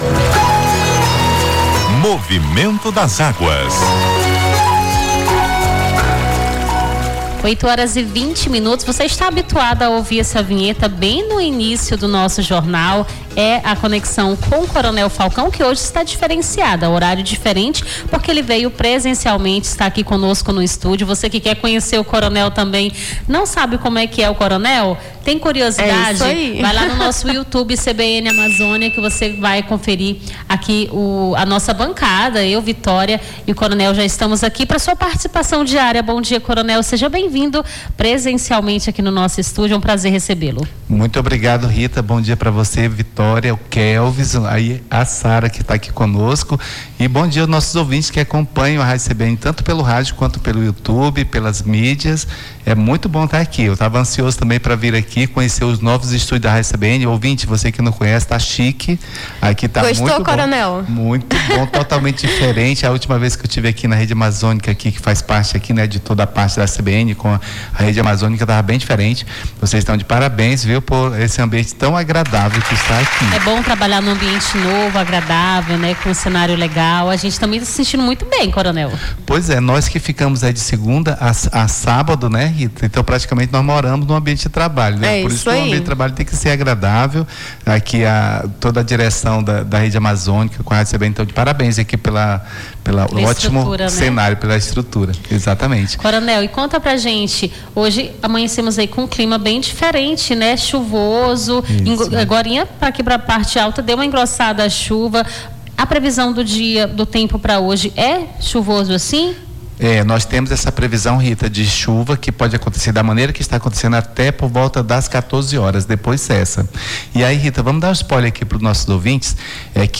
as apresentadoras